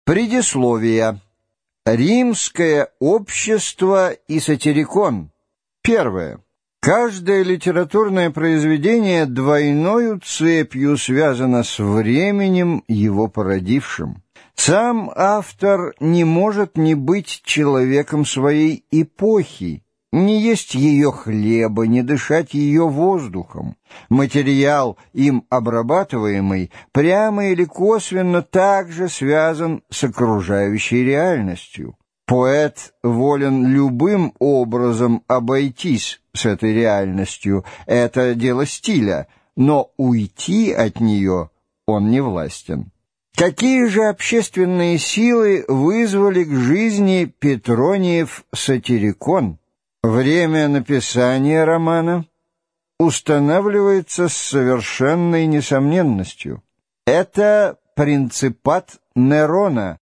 Аудиокнига Сатирикон | Библиотека аудиокниг